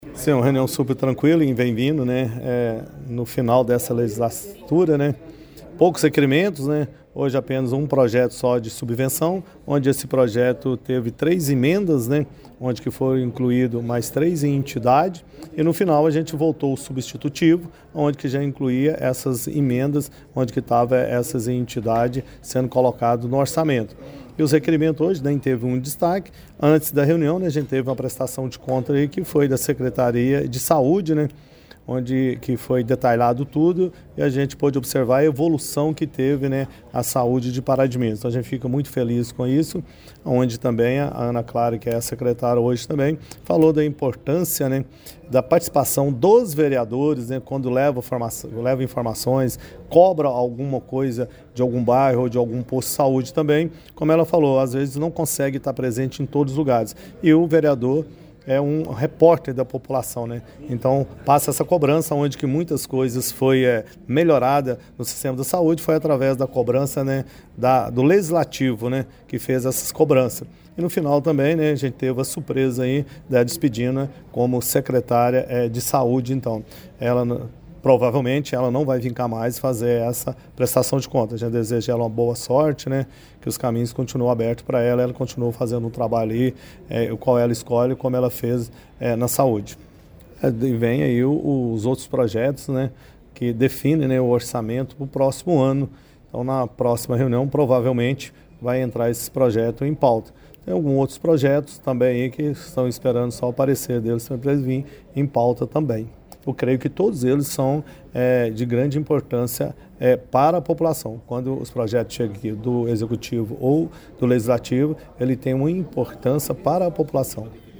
O presidente da Mesa Diretora da Câmara Municipal de Pará de Minas, Dilhermando Rodrigues Filho, falou sobre a reunião, com destaque para a prestação de contas da Secretaria Municipal de Saúde, realizada ontem (26), um pouco antes de iniciar a reunião dos vereadores: